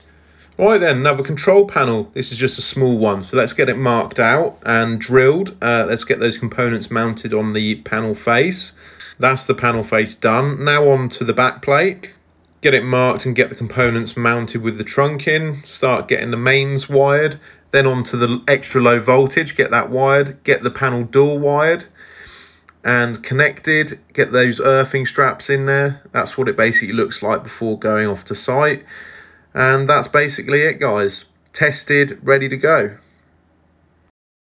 A quick control panel talk through sound effects free download